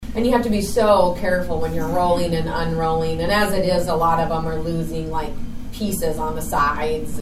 This is Franklin County Auditor Katy Flint.